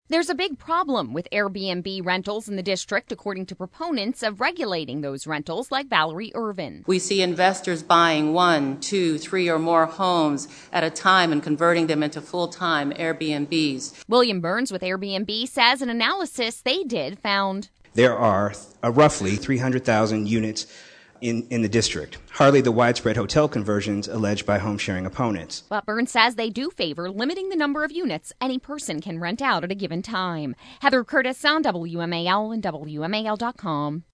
WASHINGTON — (WMAL) It was an overflow crowd at a D.C. Council meeting Wednesday about proposed regulations to Airbnb rentals in the District.